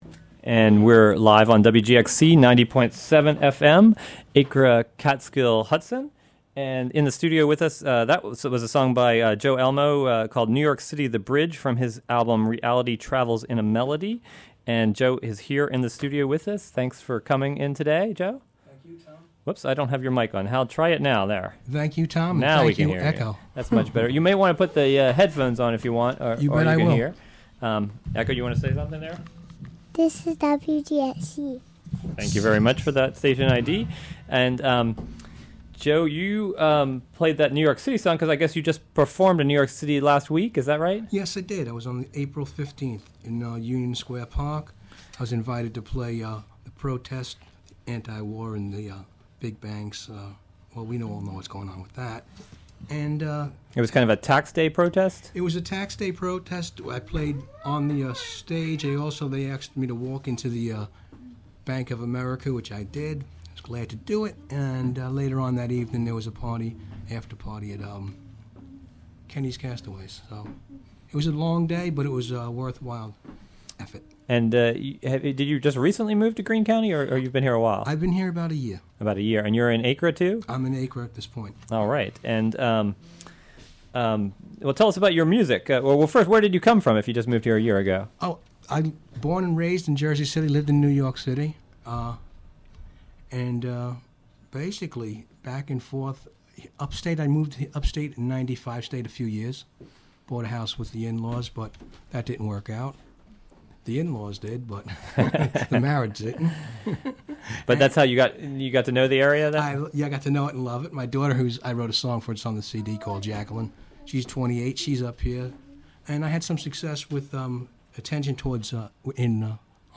performance and interview.